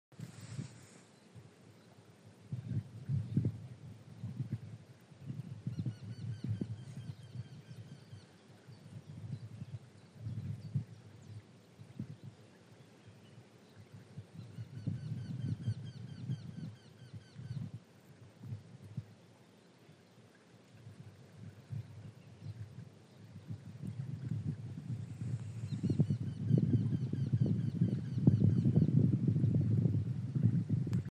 Wryneck, Jynx torquilla
Administratīvā teritorijaTērvetes novads
StatusSinging male in breeding season